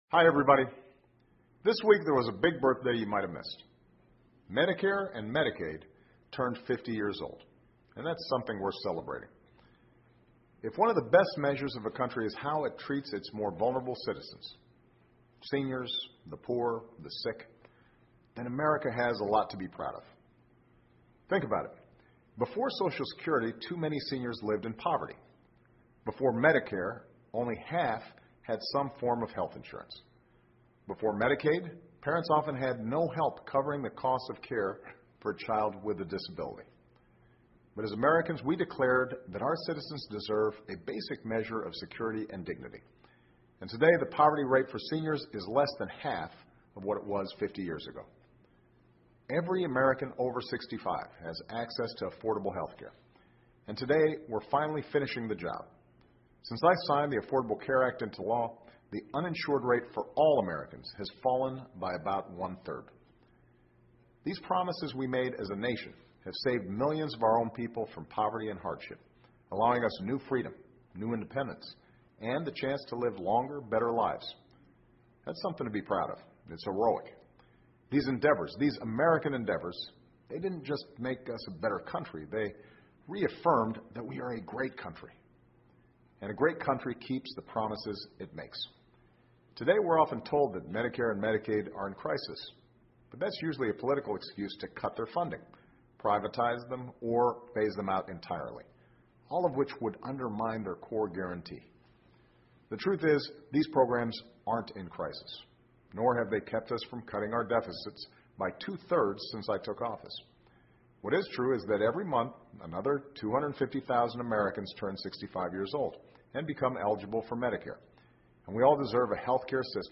奥巴马每周电视讲话：医疗保险制度50周年 总统发表讲话 听力文件下载—在线英语听力室